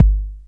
etwy_sub_kick.wav